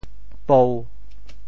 “夫”字用潮州话怎么说？
pou1.mp3